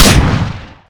new L6 SAW sounds
lmgshot.ogg